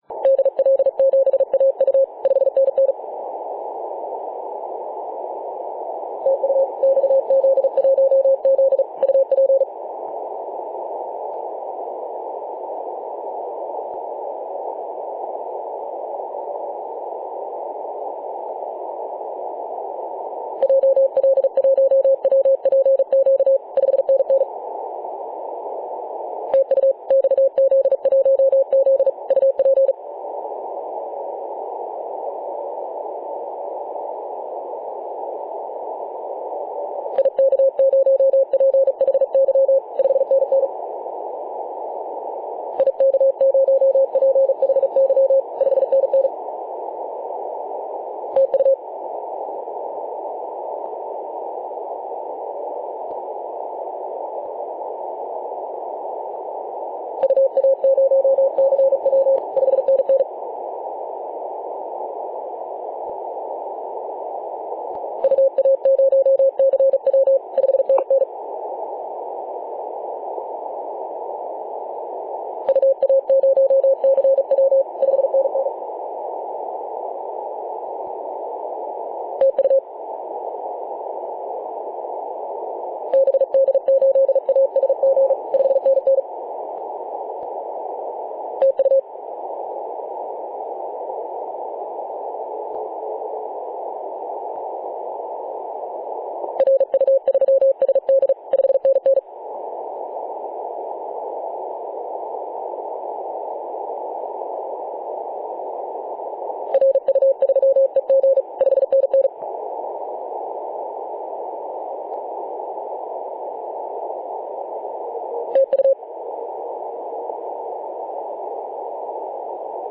2013/Sep/22 0824z XZ1Z 24.896MHz CW